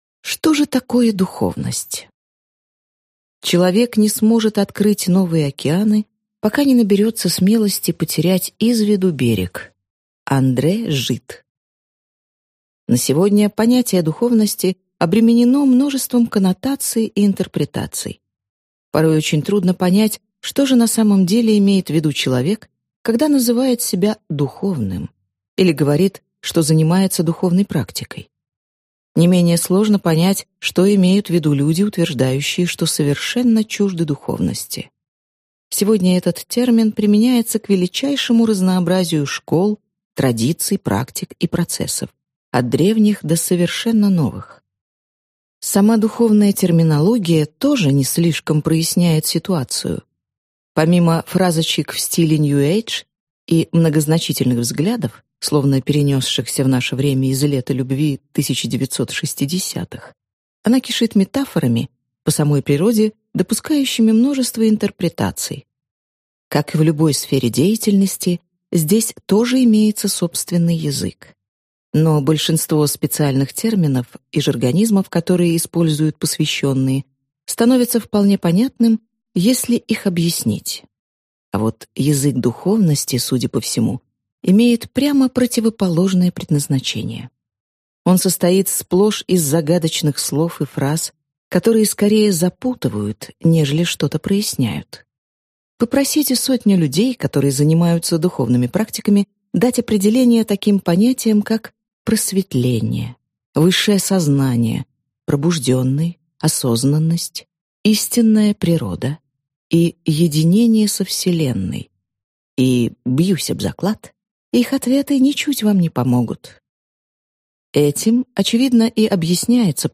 Аудиокнига Духовный интеллект. Как SQ помогает обойти внутренние блоки на пути к подлинному счастью | Библиотека аудиокниг